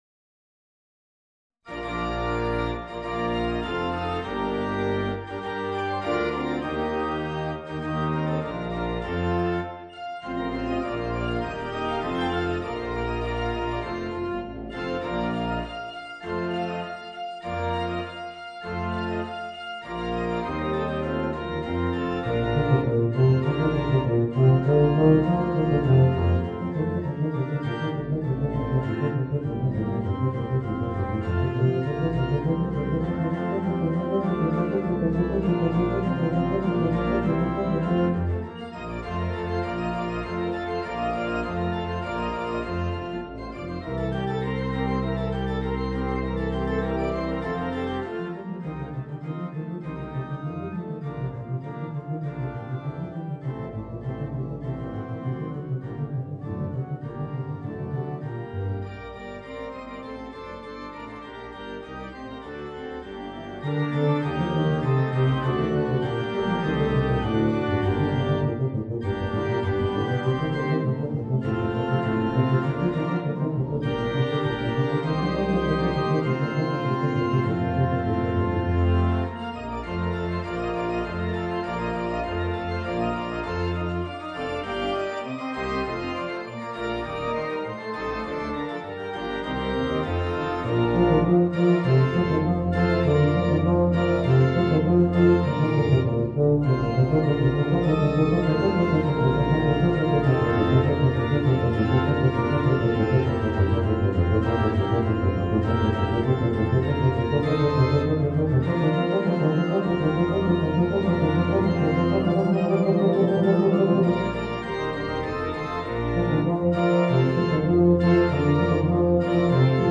Voicing: Eb Bass and Organ